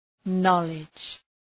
Προφορά
{‘nɒlıdʒ}